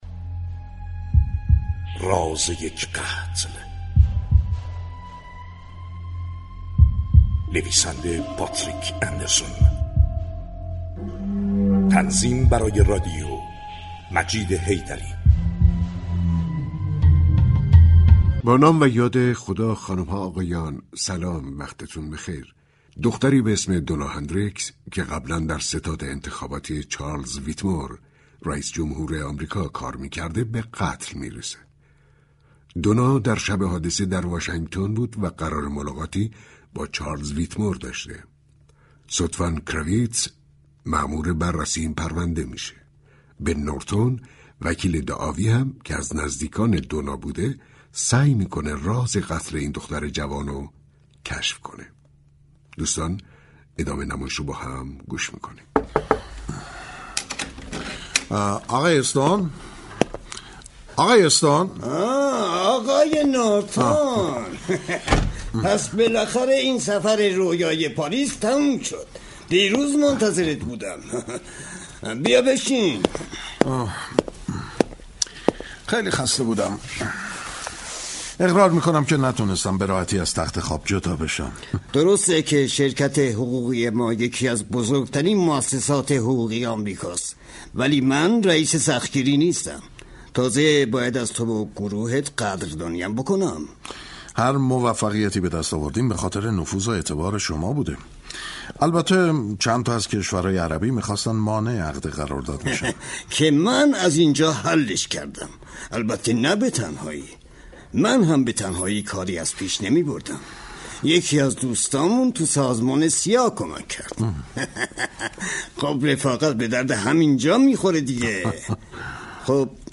علاقه مندان به سریال های پلیسی می توانند از روز یك شنبه 16 اردیبهشت ماه، شنونده سریال رادیویی